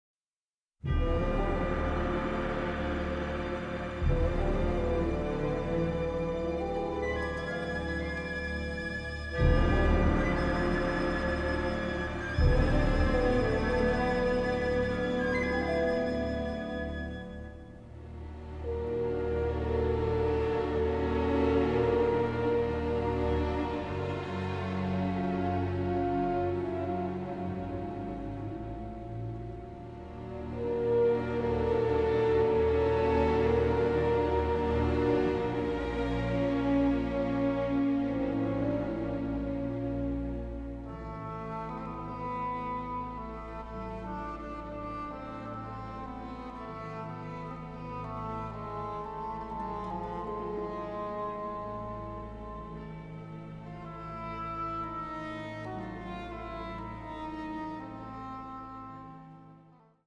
symphonic score
a sensitive, powerful, highly melodic score
from the original album stereo master tapes